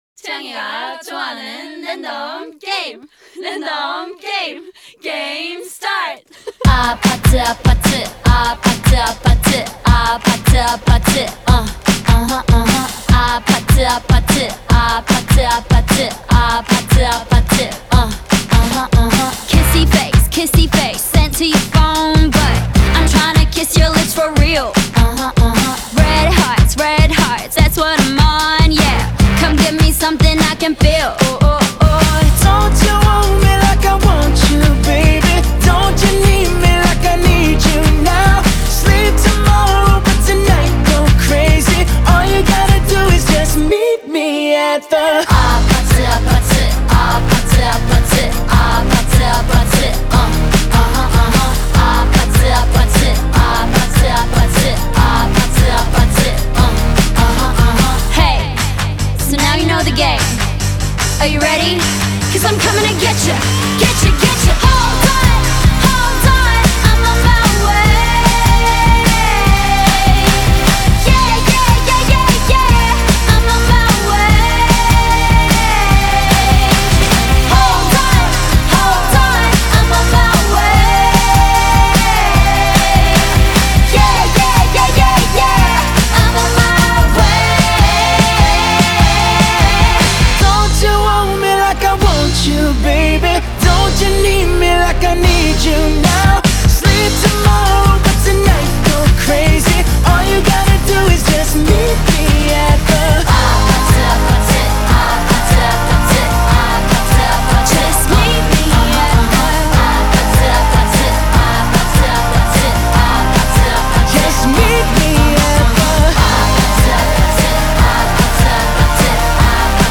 BPM75-149
Audio QualityMusic Cut